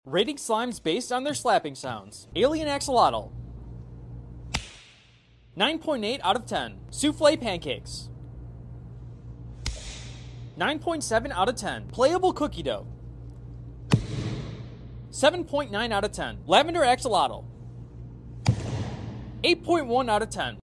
Which slime slap sounded the sound effects free download
Which slime slap sounded the loudest?